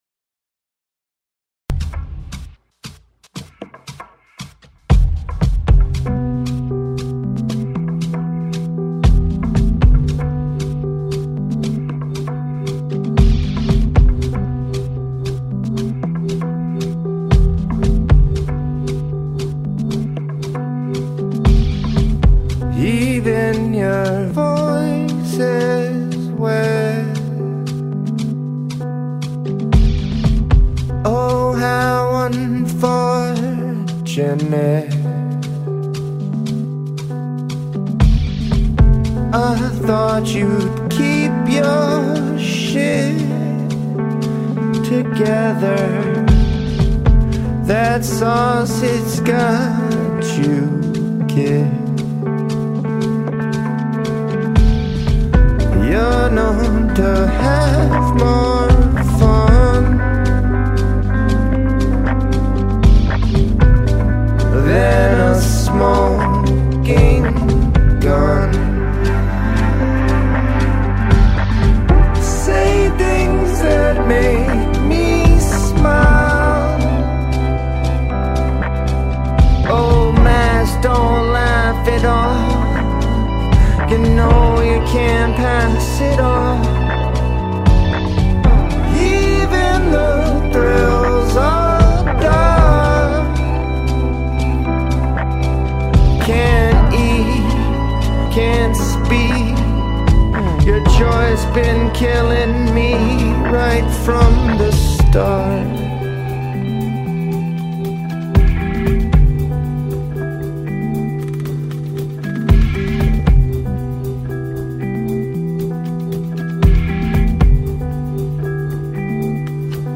an electro-acoustic collaborative project
Written and recorded in New York City and Los Angeles